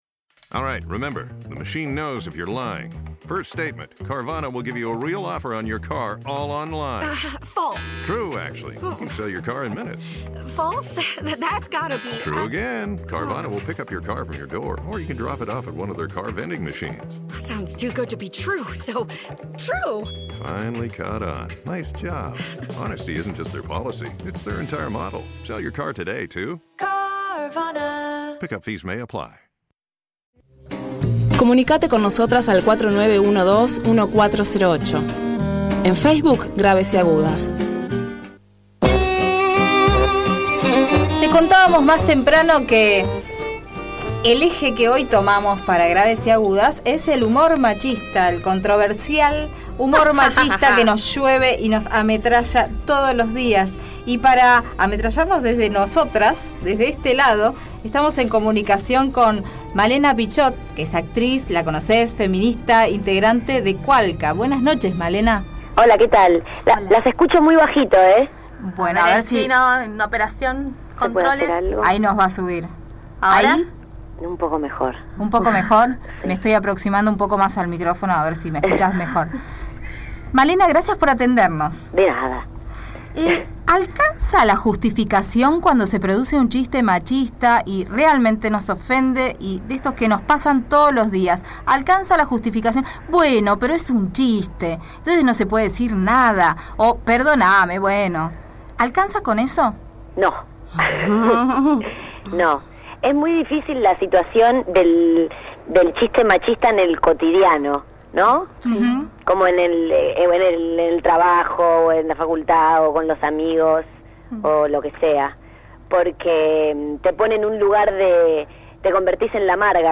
Entrevista Malena Pichot | 'La figura del humorista argentino es una basura'